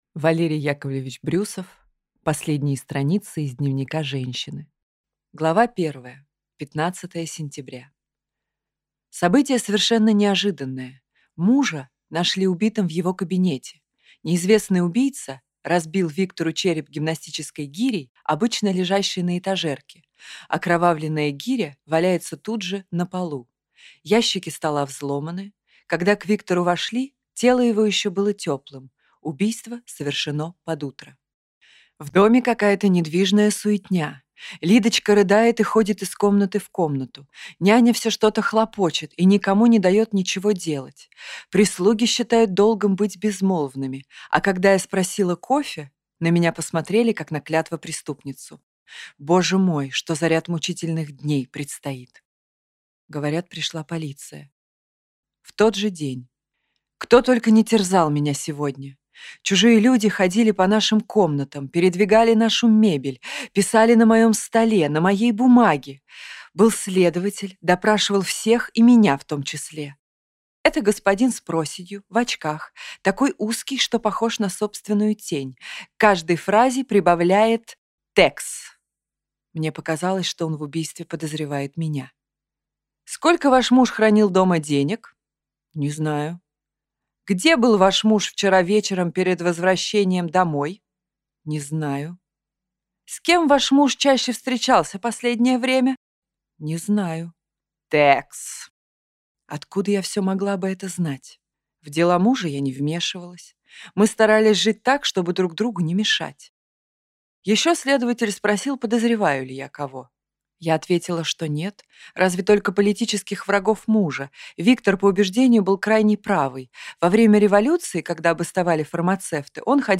Аудиокнига Последние страницы из дневника женщины | Библиотека аудиокниг